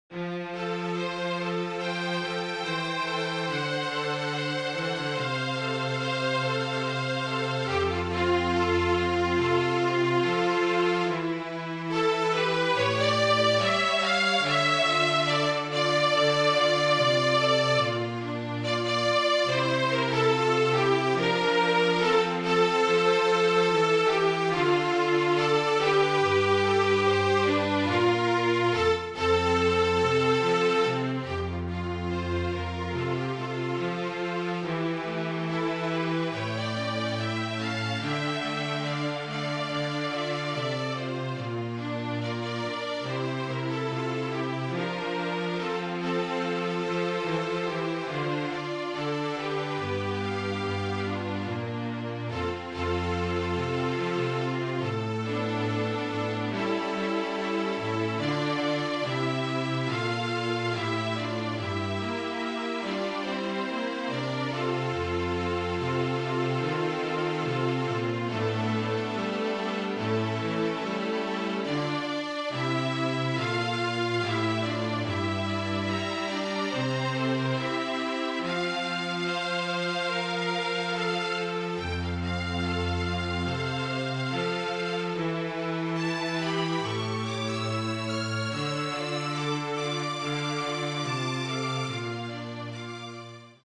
FLUTE QUARTET
(Flute, Violin, Viola and Cello)
MIDI